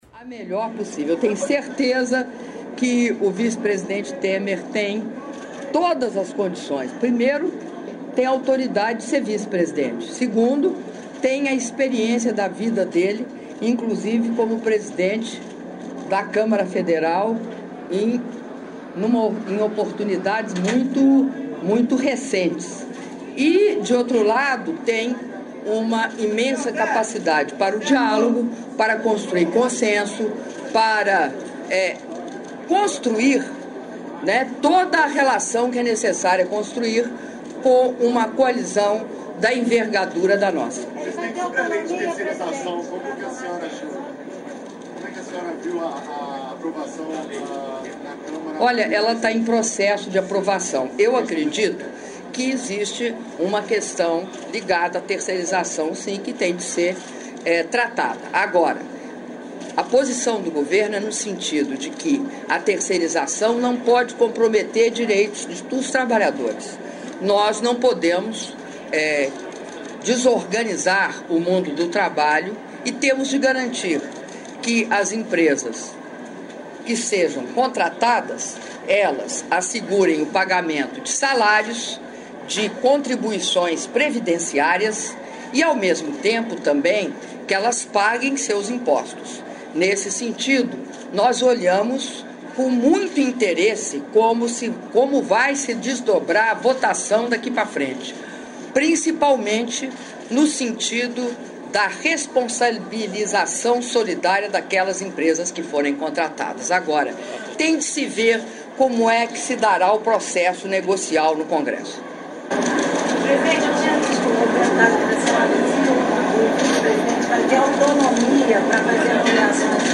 Áudio da entrevista coletiva concedida pela Presidenta da República, Dilma Rousseff, após cerimônia de entrega de 500 unidades habitacionais do Residencial Volterra, do Programa Minha Casa Minha Vida - Duque de Caxias/RJ